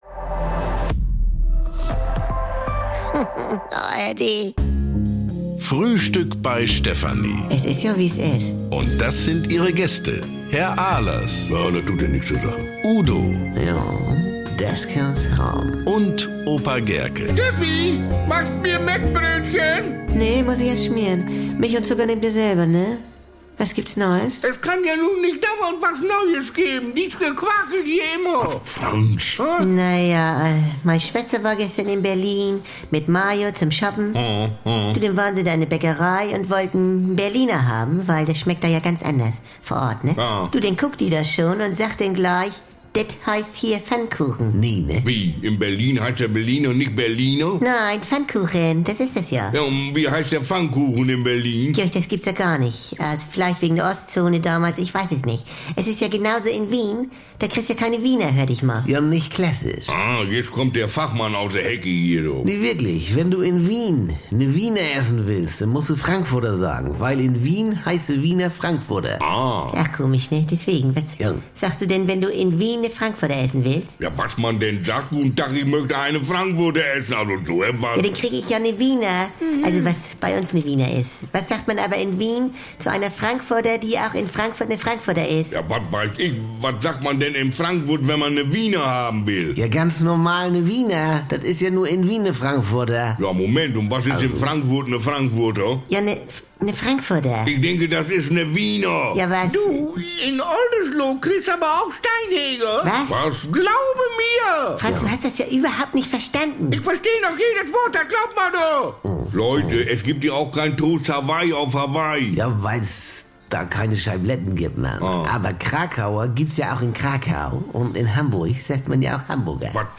Frisch geschmierte Mettbrötchen, Schnorrer-Tipps, Pyro-Fantasien und brummeliges Gemecker bekommt ihr jeden Tag im Radio oder jederzeit in der ARD Audiothek.